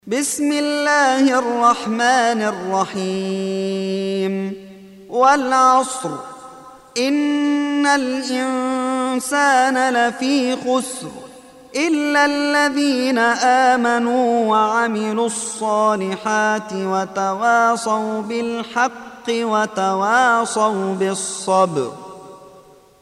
103. Surah Al-'Asr سورة العصر Audio Quran Tarteel Recitation
حفص عن عاصم Hafs for Assem